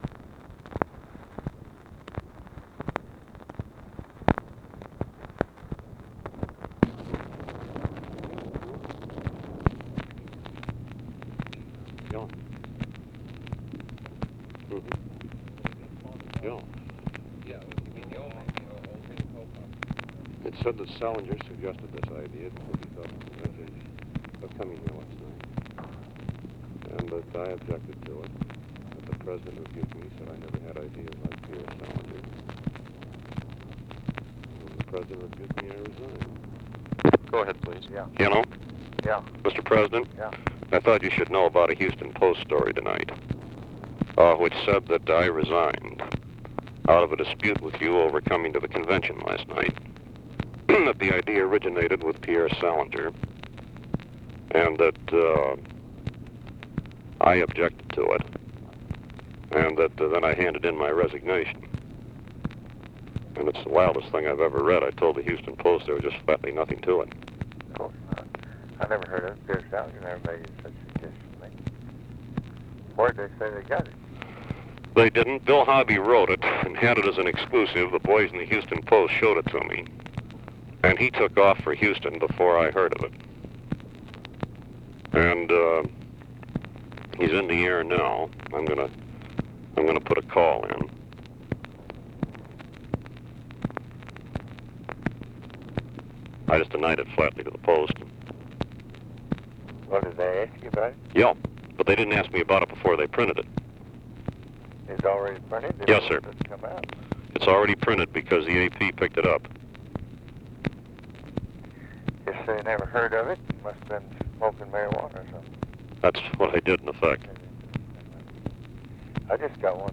Conversation with GEORGE REEDY and OFFICE CONVERSATION, August 28, 1964
Secret White House Tapes